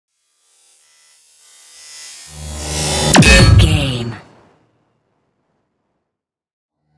Sci fi whoosh to hit shot
Sound Effects
dark
futuristic
intense
woosh to hit
the trailer effect